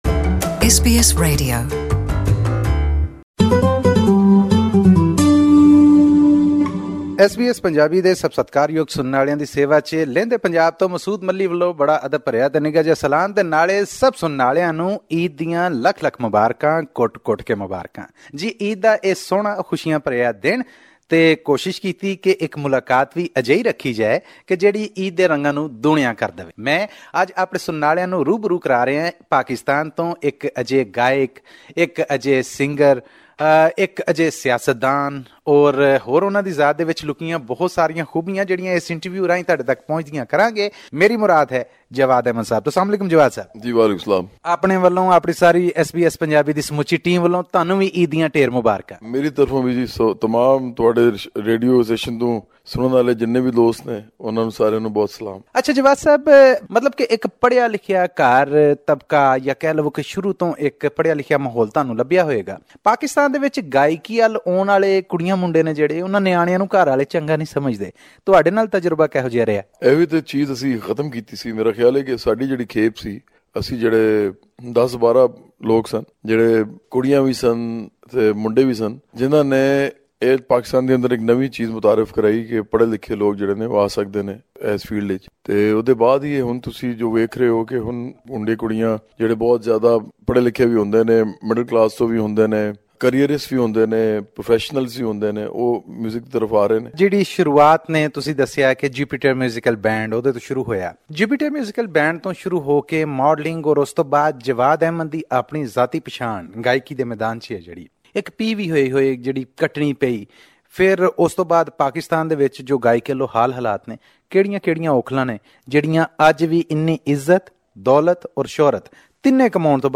This Eid, our Pakistan correspondent takes us to Lahore where we meet famous singer Jawad Ahmad, whose songs in Punjabi and Urdu have created waves not only in his home country but also across the border in India. Listen to this interview in Punjabi by clicking on the player at the top of the page.